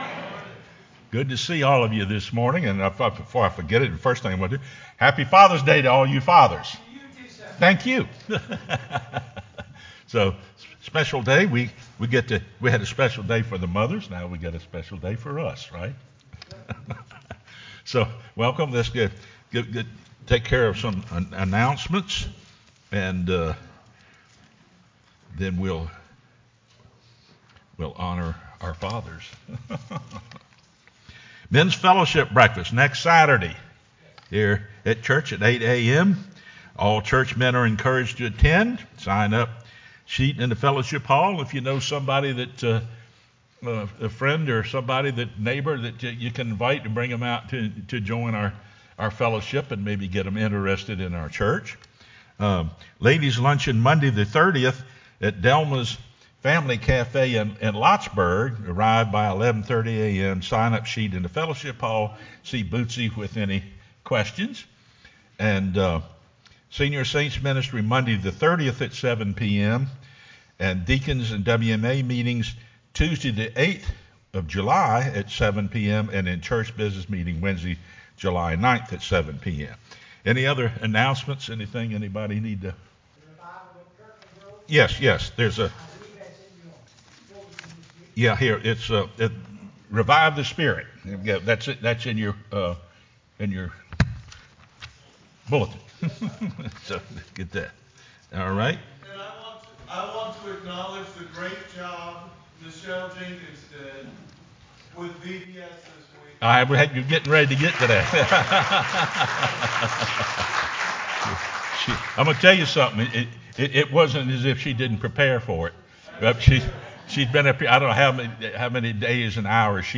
sermonJun15-CD.mp3